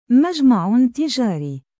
音标: /ˈmadʒmaʕ tiˈdʒaːriː/